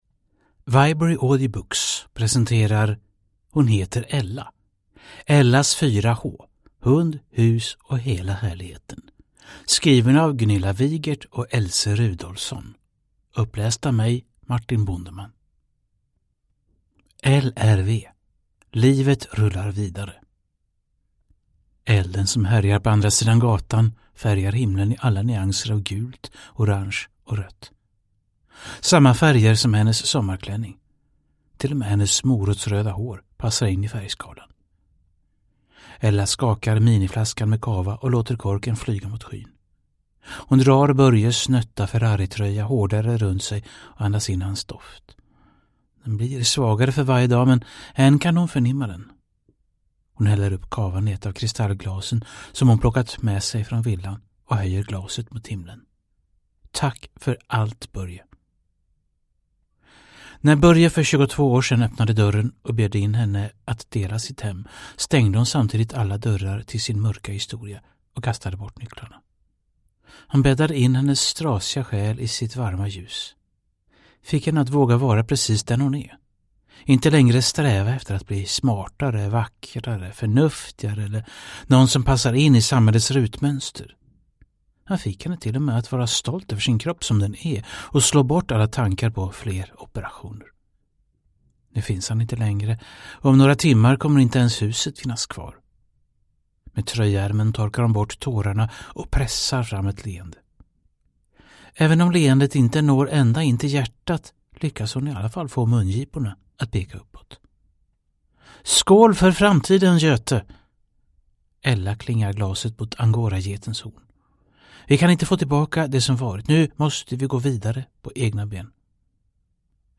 Hon heter Ella (ljudbok) av Gunilla Vigert